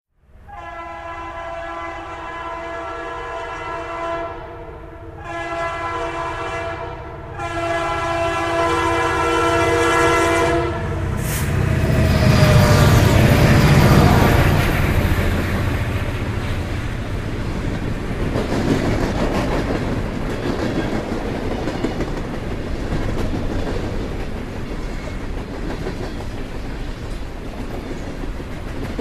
train-pass_25234.mp3